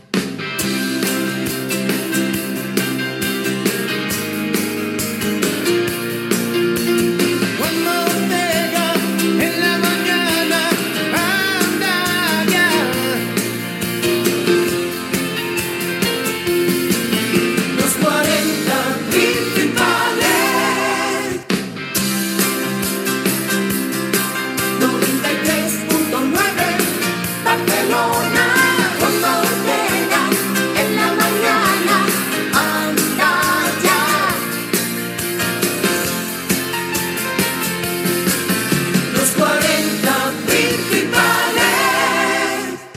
FM